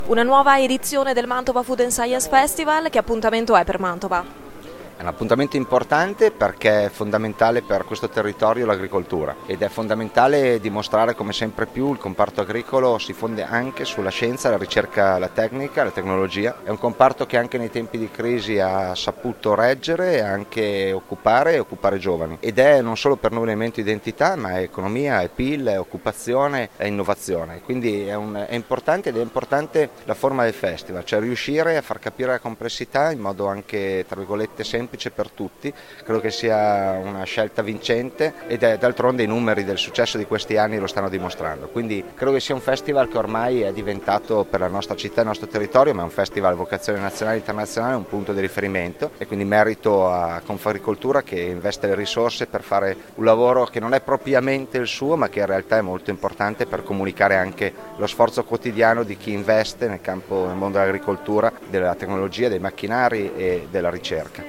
il sindaco di Mantova, Mattia Palazzi: